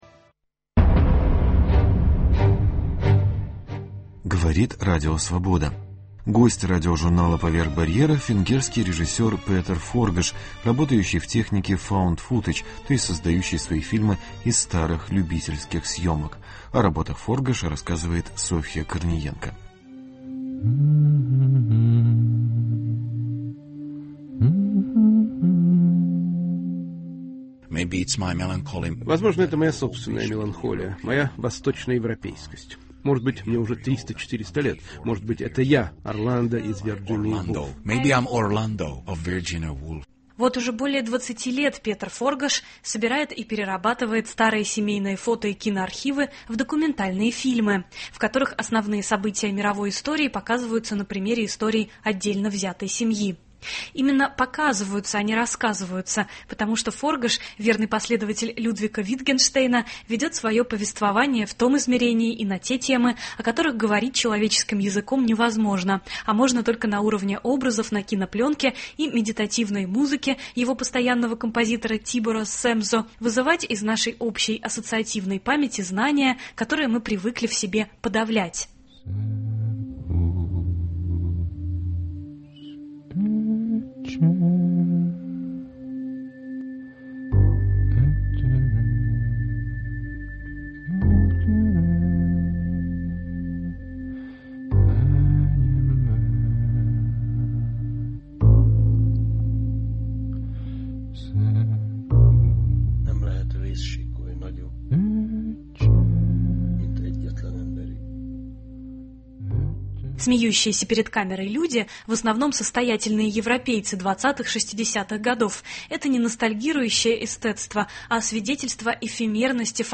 Интервью с кинорежиссером Петером Форгашем.